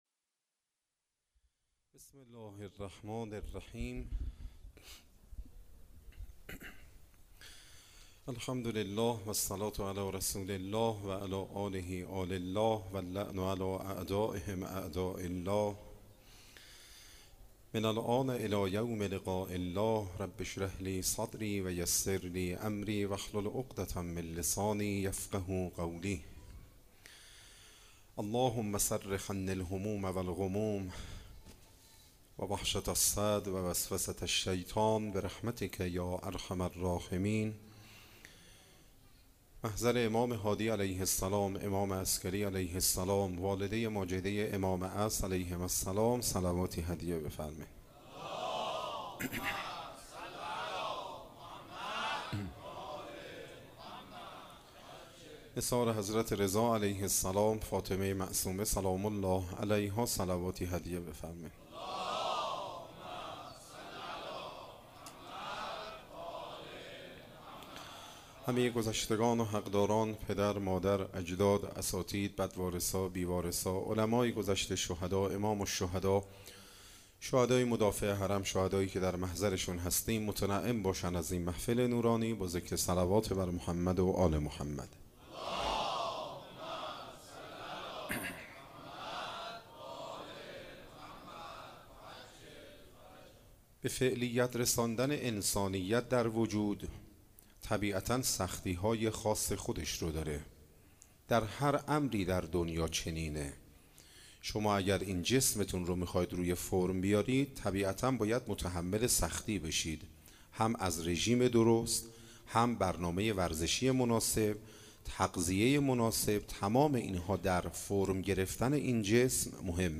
شب چهارم ماه رمضان 96_هیئت رزمندگان غرب
سخنرانی